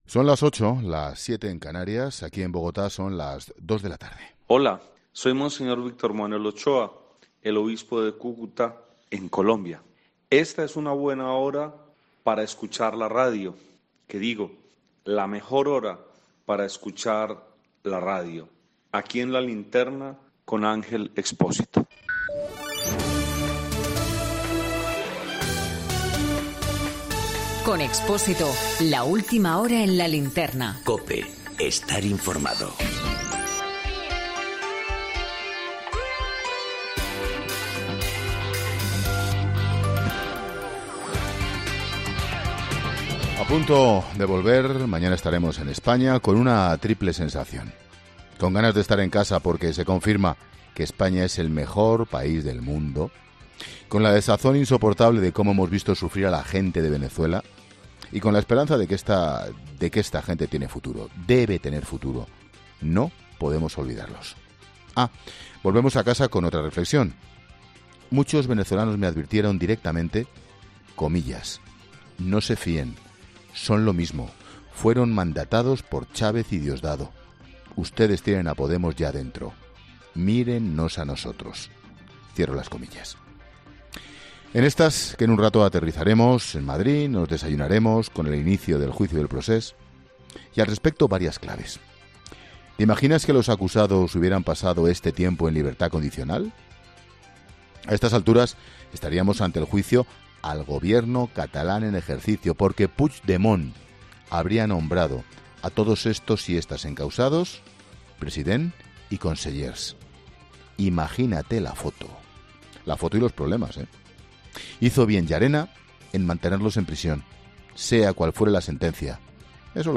Monólogo de Expósito
El análisis de la actualidad de este lunes con Ángel Expósito en 'La Linterna' desde la frontera con Venezuela